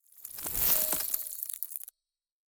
Free Frost Mage - SFX
ice_whoosh_22.wav